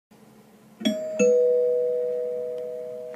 Doorbell sound effect